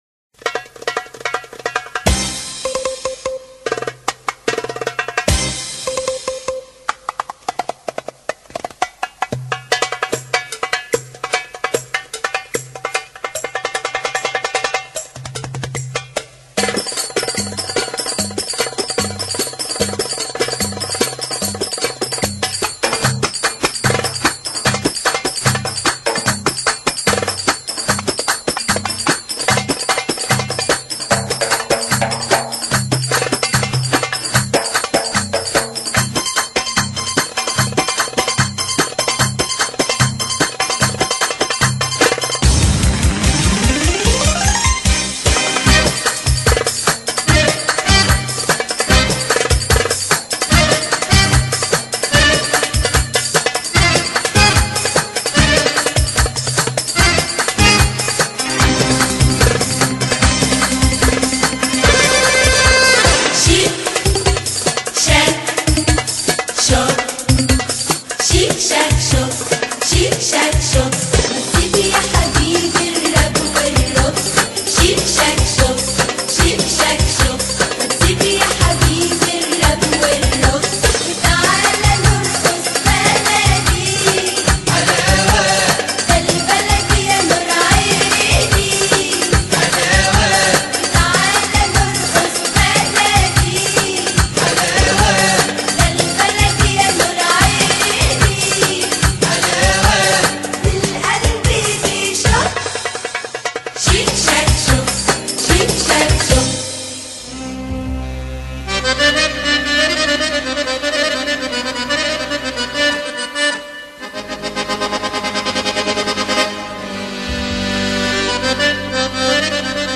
Egyptian Bellydance